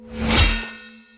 🌲 / foundry13data Data modules soundfxlibrary Combat Single Melee Hit
melee-hit-9.mp3